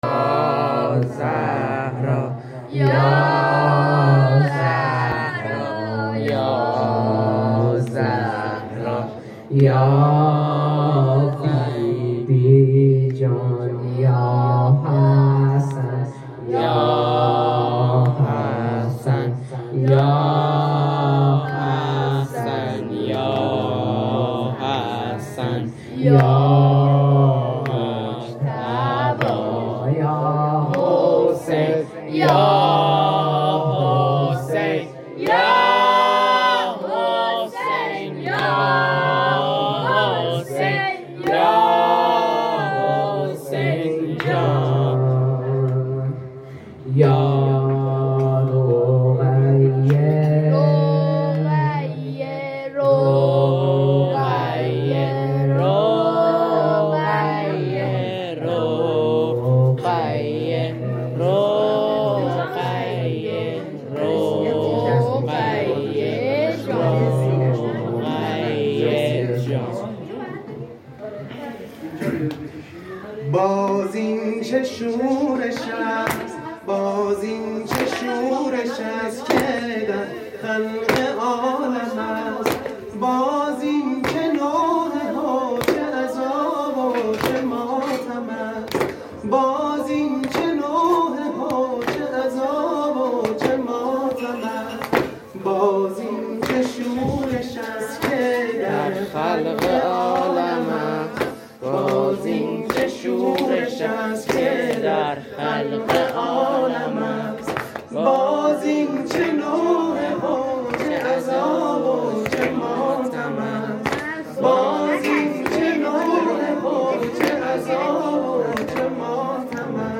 محرم الحرام 1401_شب اول